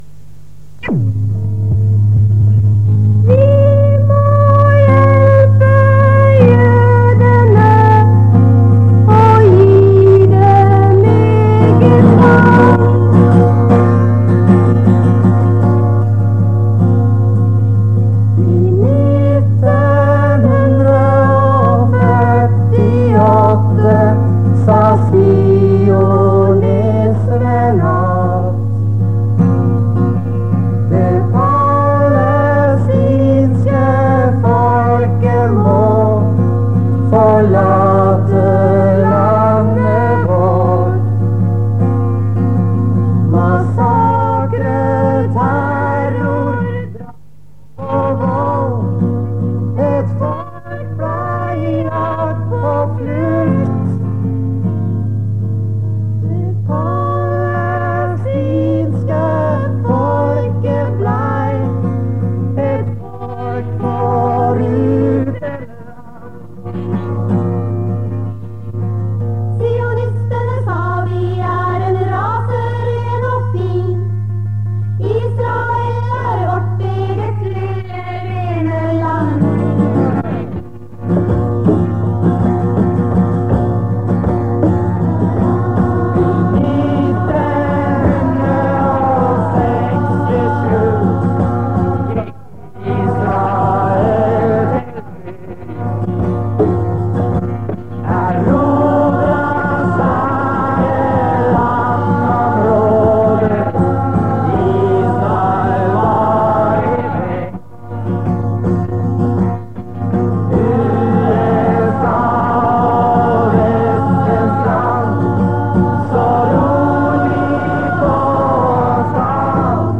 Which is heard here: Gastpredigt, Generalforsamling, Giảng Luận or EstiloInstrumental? Generalforsamling